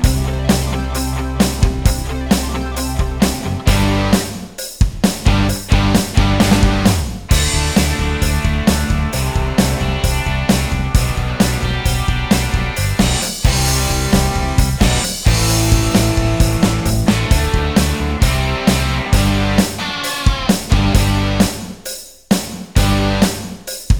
Minus Solo Guitars Rock 3:57 Buy £1.50